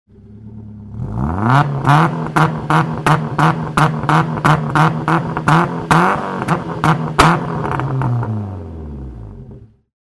Звуки старта гонки
Звук ревущего мотора: гоночный автомобиль газует перед стартом